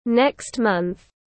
Tháng sau tiếng anh gọi là next month, phiên âm tiếng anh đọc là /nekst ˈmʌnθ/